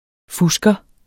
Udtale [ ˈfusgʌ ]